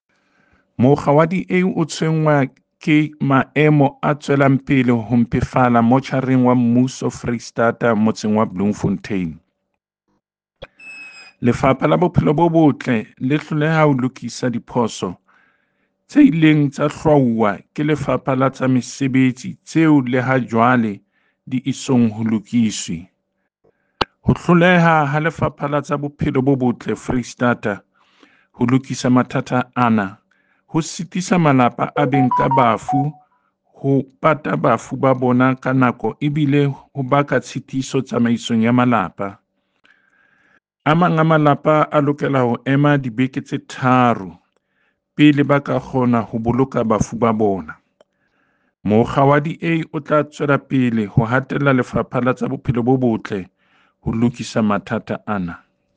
By David Masoeu | DA Spokesperson for Health in the Free State Legislature
Sesotho soundbites by David Masoeu MPL and